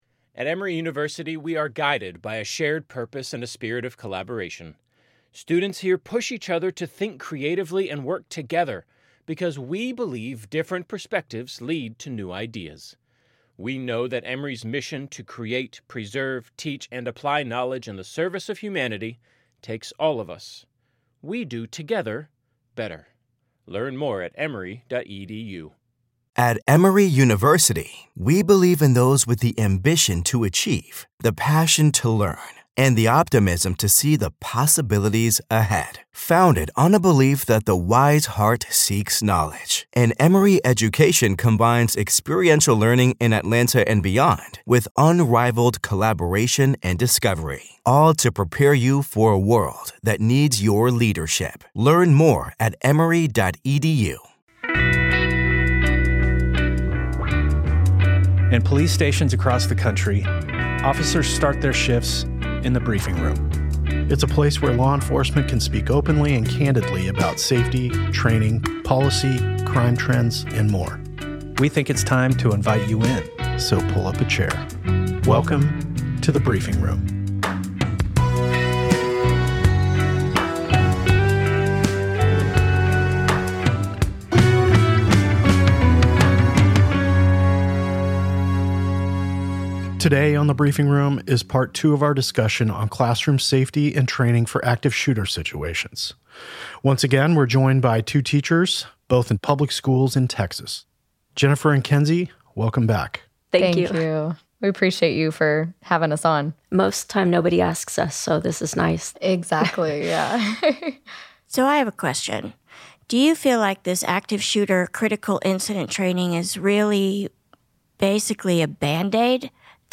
The second installment of our conversation with two Texas elementary school teachers about classroom safety in the wake of the deadly shooting at an elementary school in Uvalde, TX, along with advice from a veteran school resource officer.